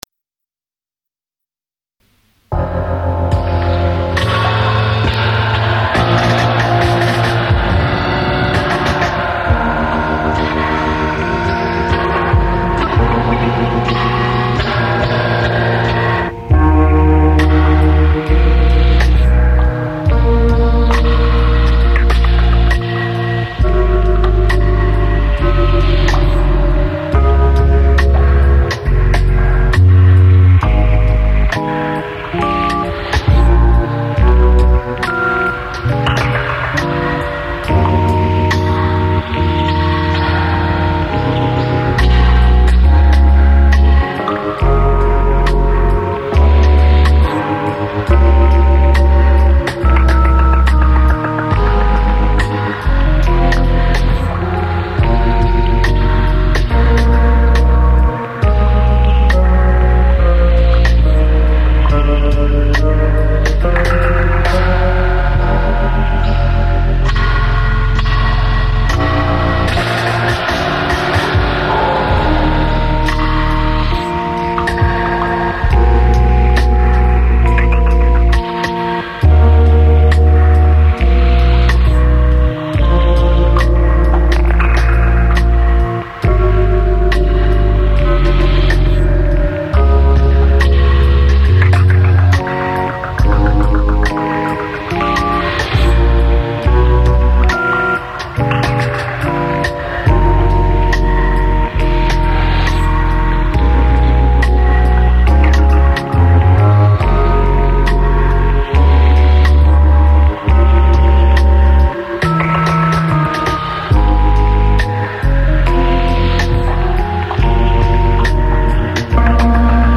eclectic mix